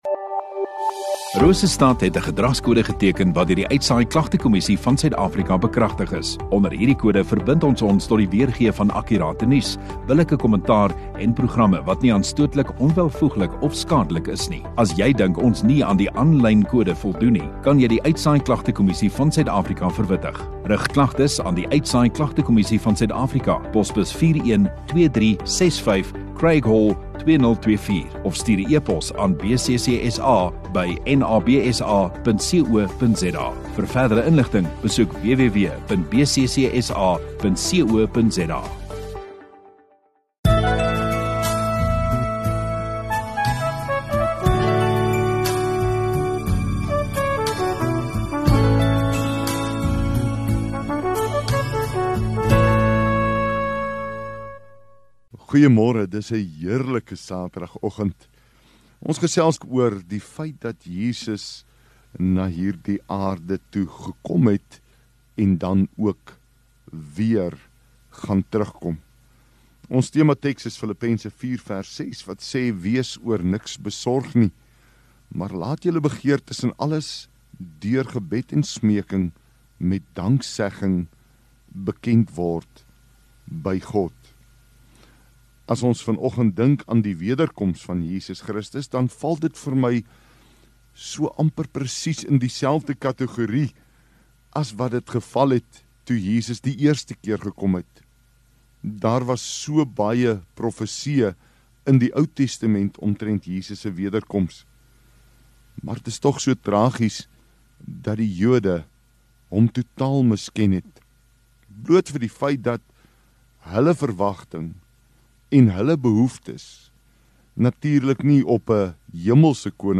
31 Aug Saterdag Oggenddiens